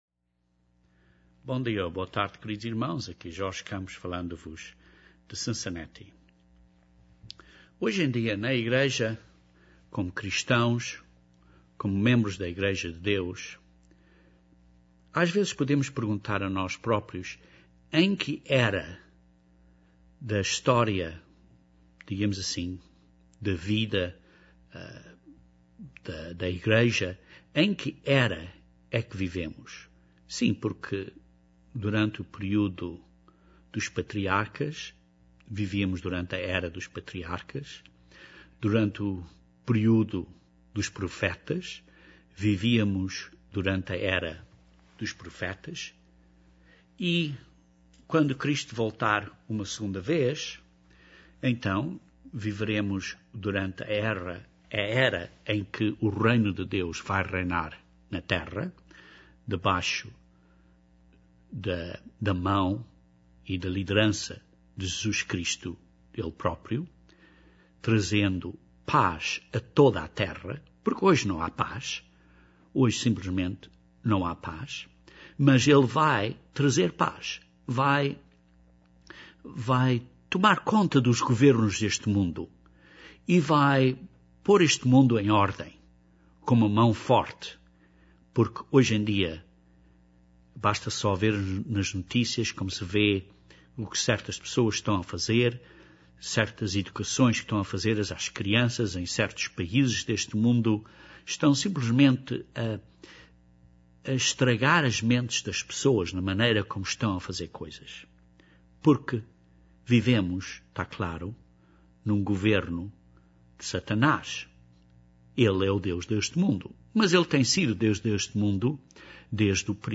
Qual foi o propósito da Igreja na era dos apóstolos? E qual é o propósito hoje? Este sermão descreve dois pontos importantes da Igreja de então e da Igreja de hoje em dia.